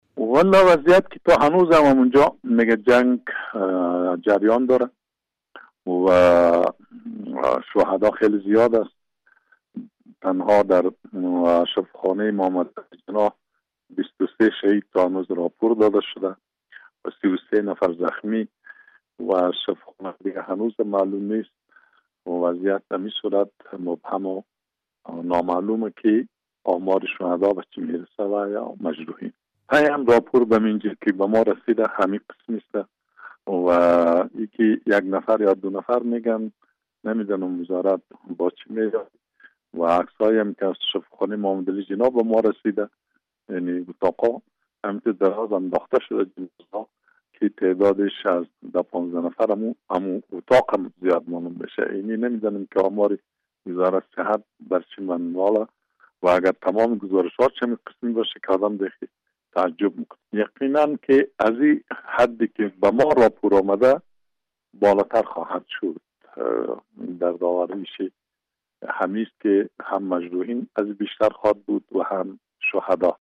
مصاحبه - صدا
آقای کریم خلیلی در مصاحبه تلفونی با رادیو آزادی در مورد جزئیات تازه شمار تلفات رویداد حمله مسلحانه بر مراسم ۲۵مین سالیاد عبدالعلی مزاری گفته است که تنها در شفاخانه محمد علی جناح ۲۳ شهید و ۳۳ نفر زخمی گزارش داده شده است.